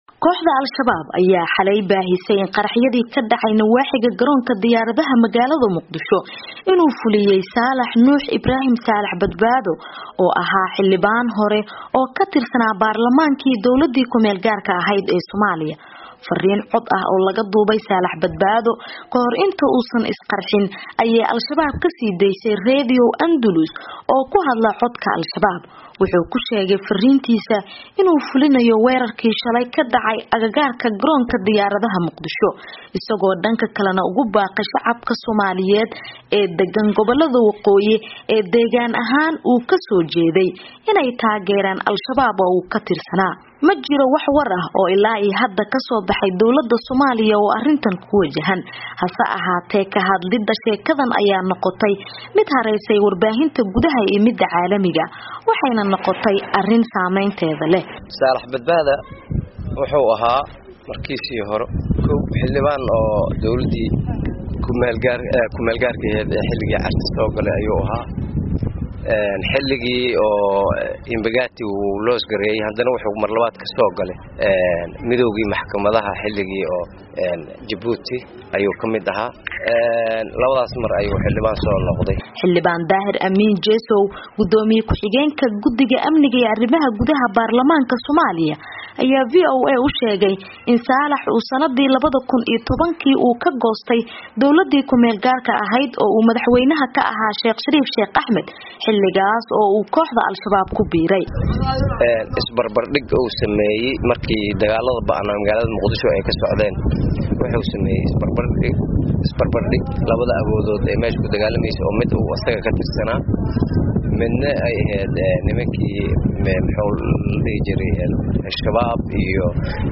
MUQDISHO —